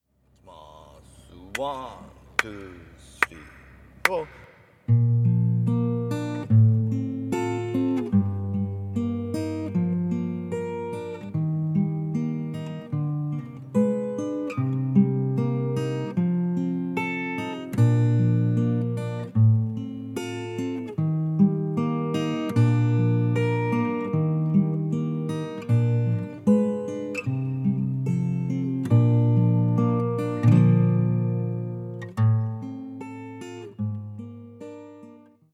しっとりとして、心に響くメロディは、オカリナの演奏にぴったりです。
上記のリンク先の音源は、サンプルのmp3ファイルで30秒程度に短く編集したものです。